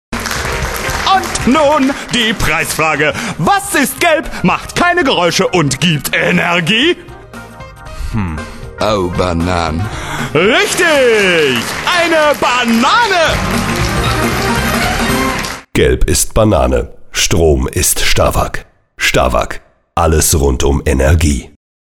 deutscher Sprecher und Sänger mit variantenreicher Stimme.
Sprechprobe: eLearning (Muttersprache):